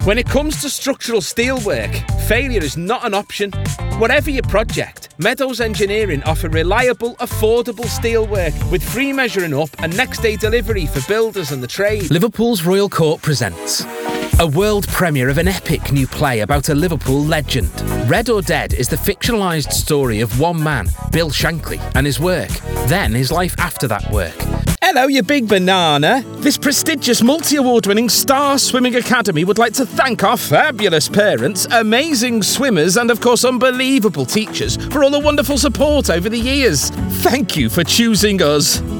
Fresh, Down to EarthVOICE QUALITYSoft, Reassuring, Confident